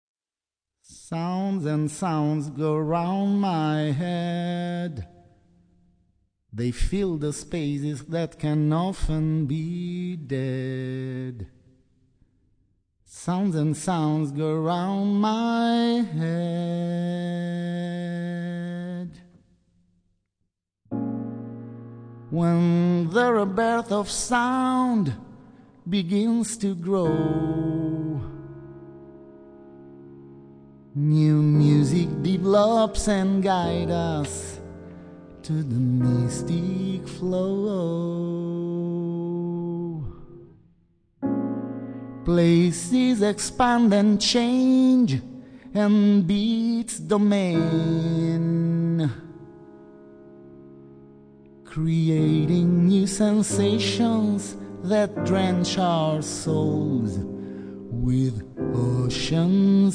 sax e voce
pianoforte
contrabbasso
Batteria